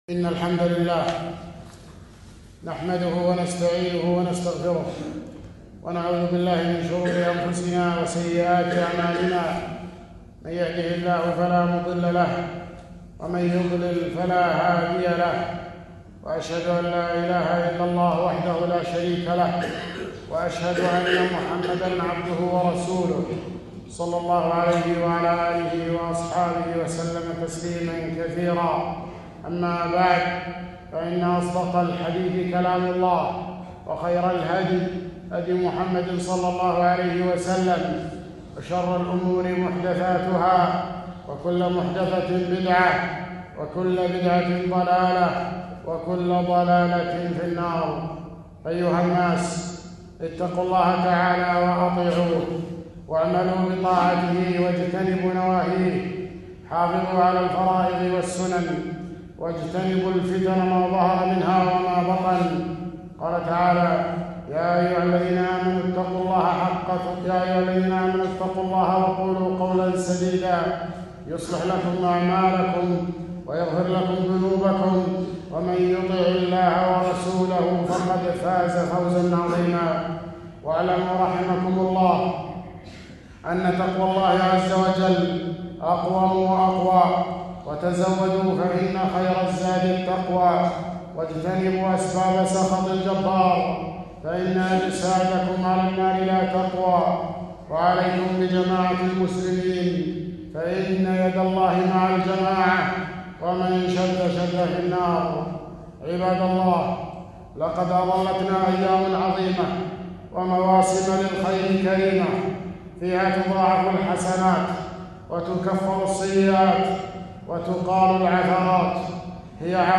خطبة - فضل عشر ذي الحجة